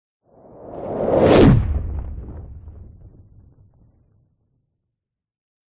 Звуки супергероя
Шум пролетающего героя над головой